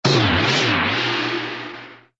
cogbldg_settle.ogg